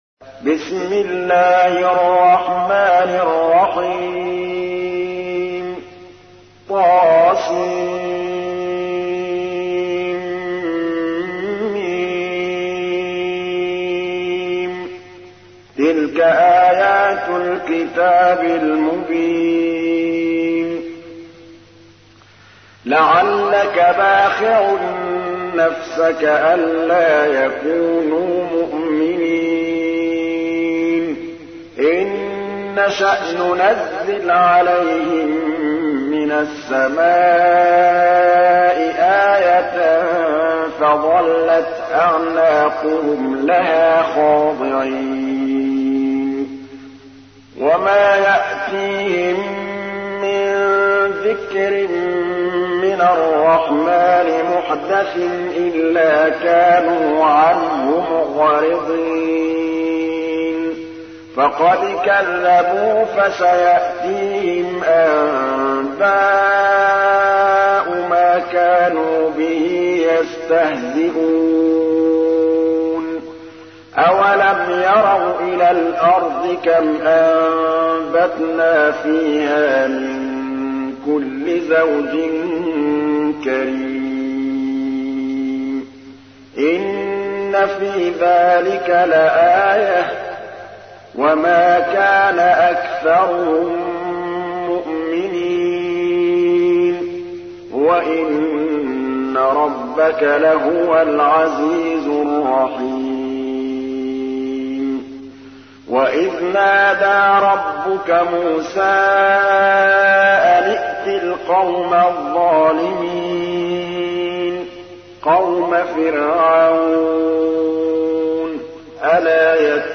تحميل : 26. سورة الشعراء / القارئ محمود الطبلاوي / القرآن الكريم / موقع يا حسين